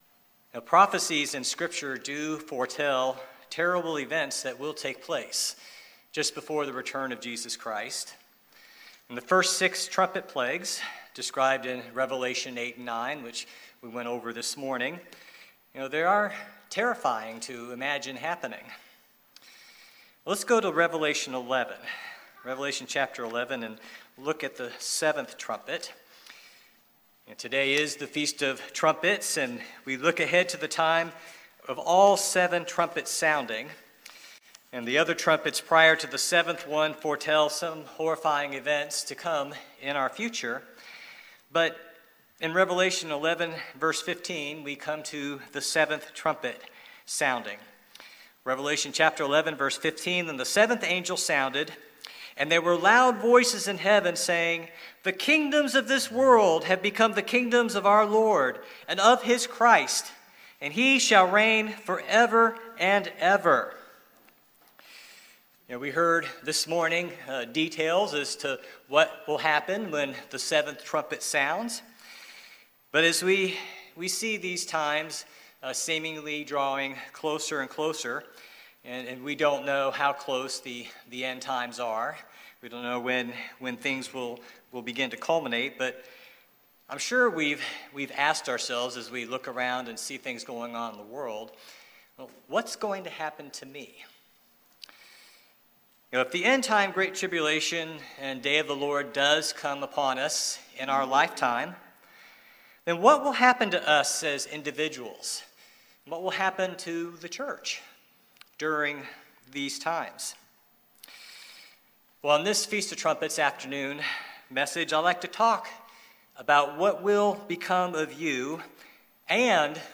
On this Feast of Trumpets afternoon, I'd like to talk about what will become of you, AND the Body of Christ during the period that we know of as the Great Tribulation and the Day of the Lord. Will God’s church be protected? Is there a place of safety?
Given in East Texas